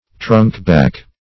trunkback - definition of trunkback - synonyms, pronunciation, spelling from Free Dictionary Search Result for " trunkback" : The Collaborative International Dictionary of English v.0.48: Trunkback \Trunk"back`\, n. (Zool.) The leatherback.